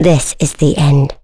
Chrisha-Vox_Skill7.wav